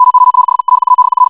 morse.wav